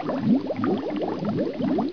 BUBBLES
Category: Animals/Nature   Right: Personal